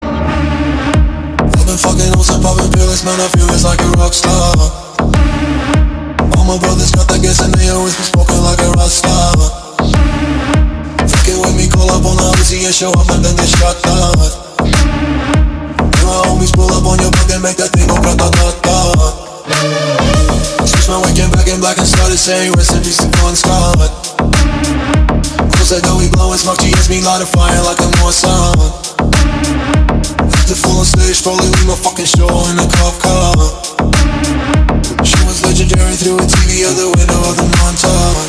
remix
deep house
G-House